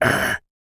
Male_Grunt_Hit_17.wav